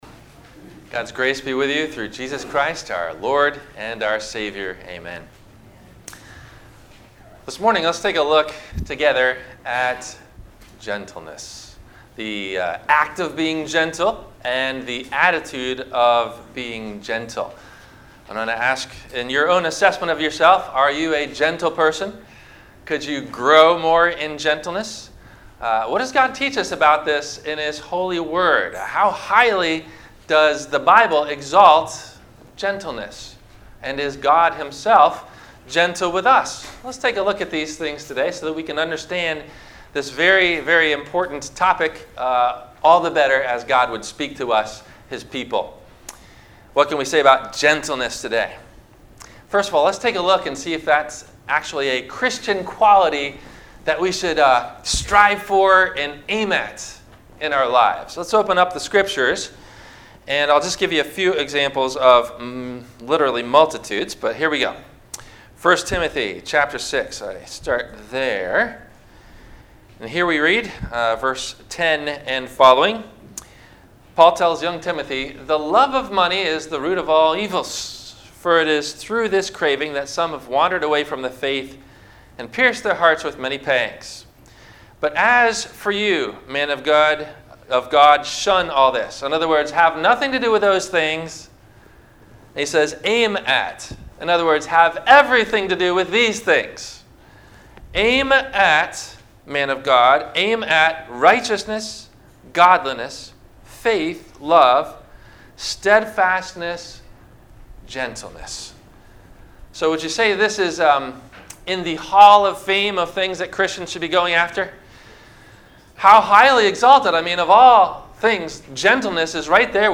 - Sermon - September 22 2019 - Christ Lutheran Cape Canaveral